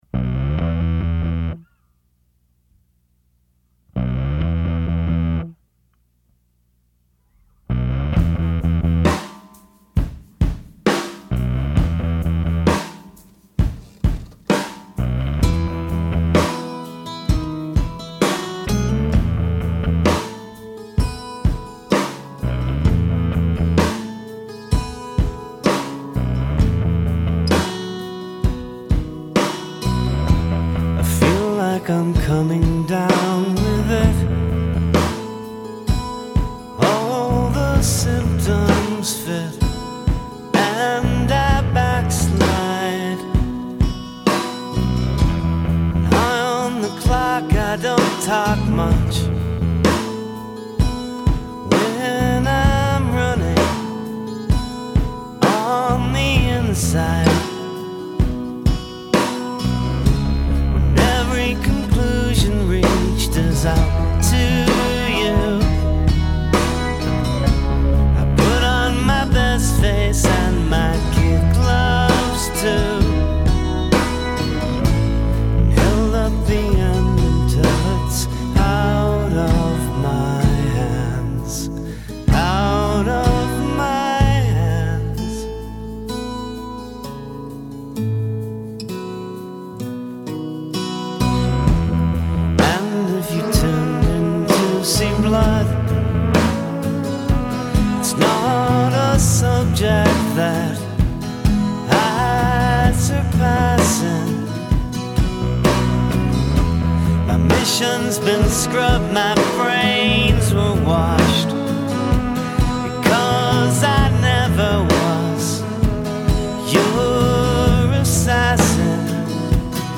literate power pop and ballads